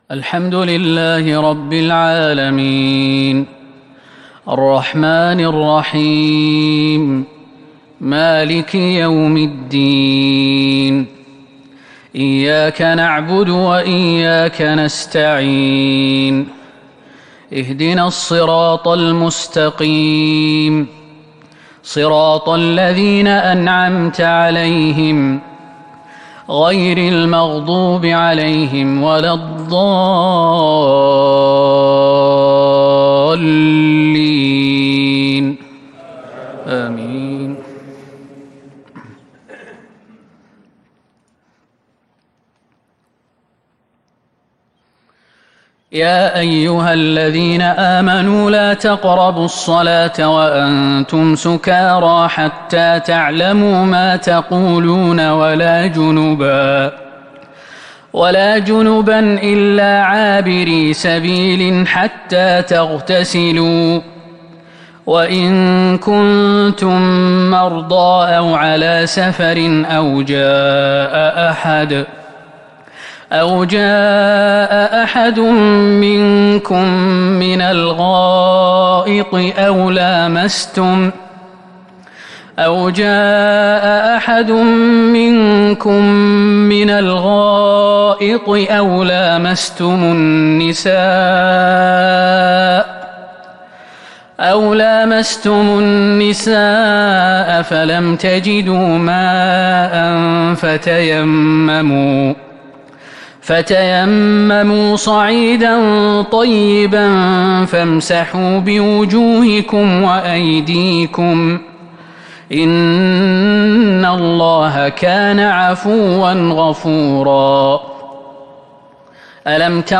صلاة الفجر من سورة النساء 43-57 | الخميس 5 ربيع الأول ١٤٤٢ه‍ـ | Fajr prayer from Surat An-nisa 43-57 | Thursday 22/10/2020 > 1442 🕌 > الفروض - تلاوات الحرمين